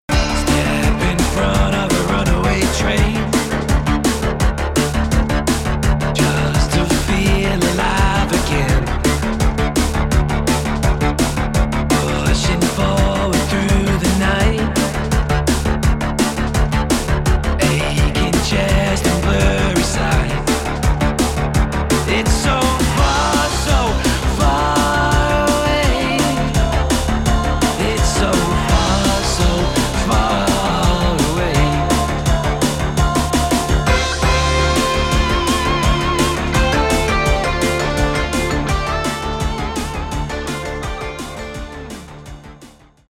CLASSIC SONG COVERS
I always felt it would work in an Electronica style.
Fast paced with a bit of 80’s attitude 😄.
Backing vocals…
Keyboards, Guitars and Drum programming
‘63 Fender Precision Bass